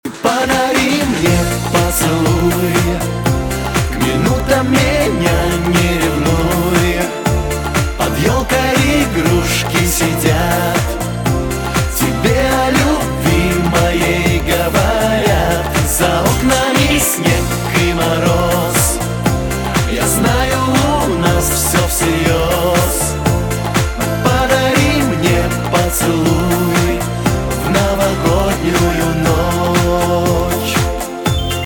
Жанр: Поп